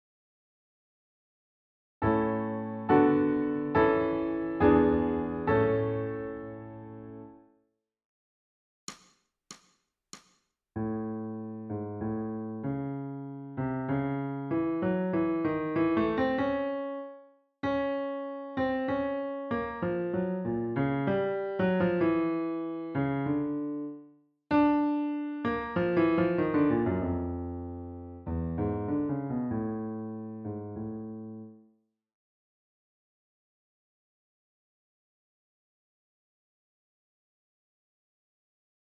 ソルフェージュ 聴音: 2-1-22